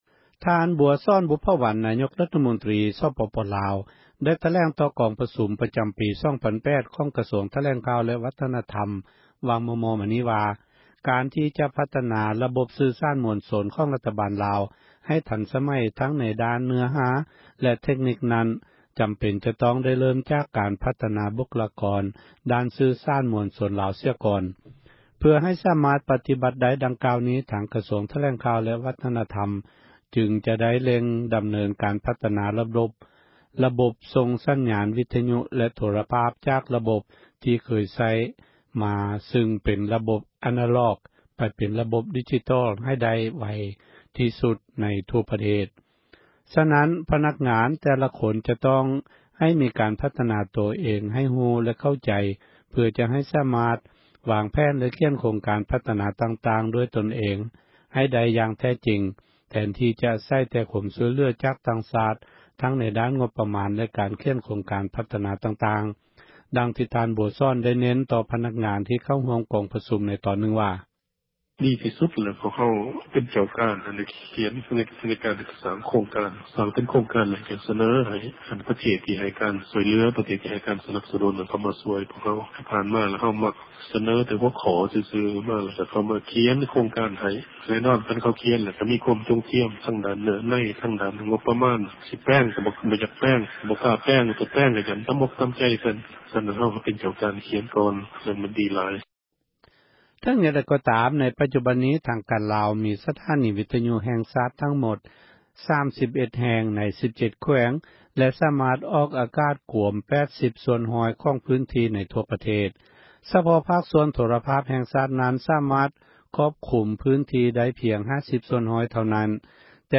ຣາຍງານ
ສຽງອັຕະໂນນາໂຖ